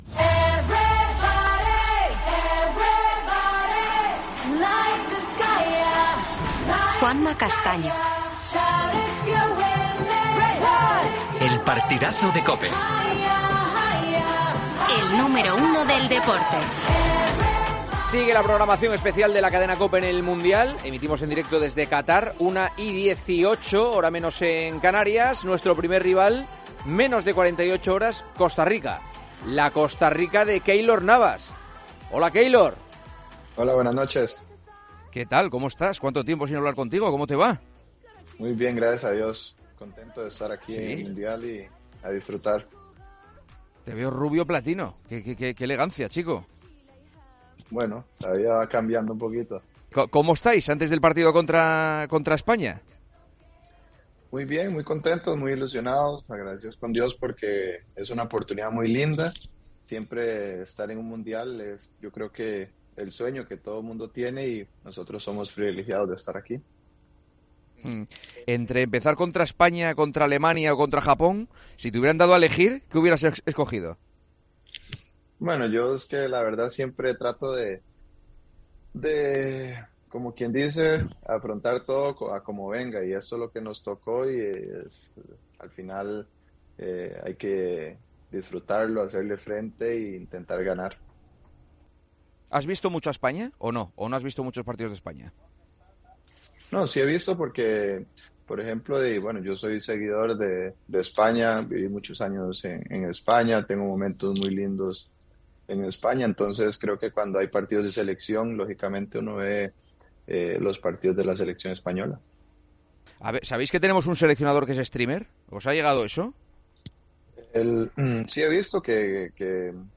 El guardameta pasó este lunes por El Partidazo de COPE en la previa del España - Costa Rica.